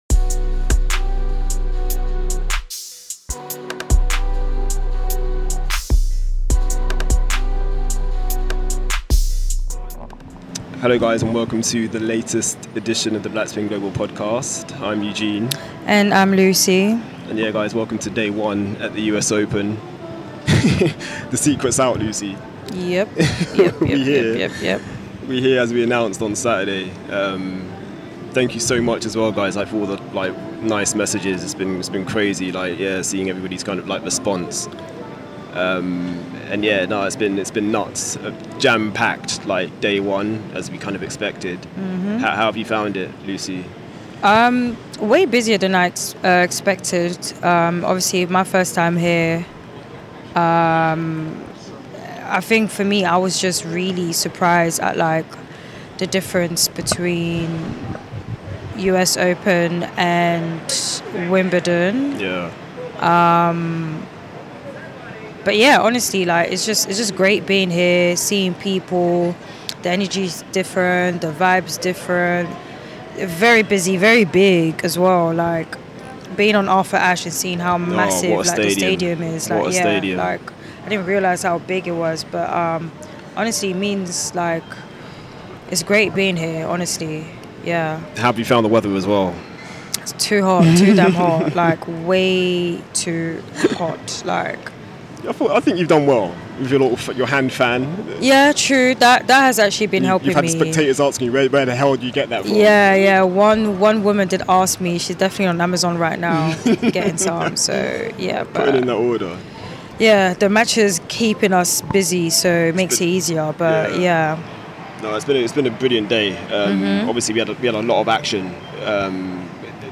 Listen out for interview snippets from Shelton, Gauff and Townsend.